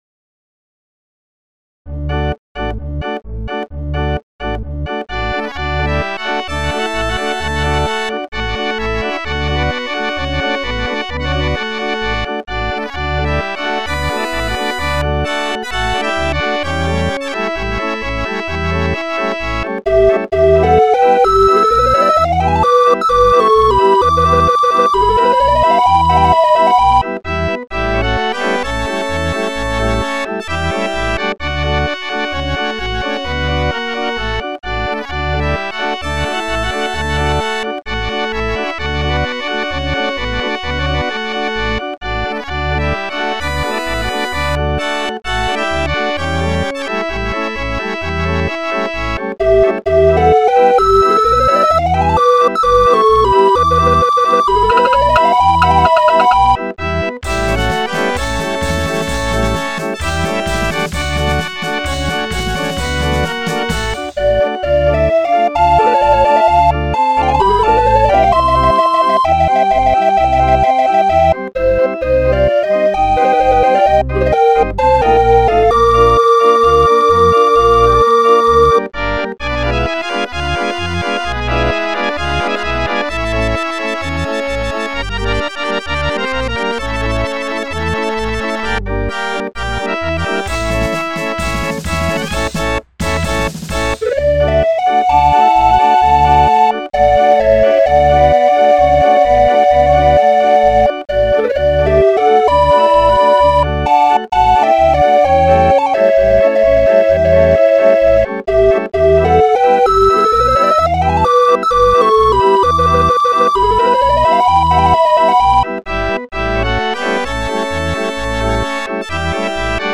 Music rolls, music books and accessoires for barrel organs.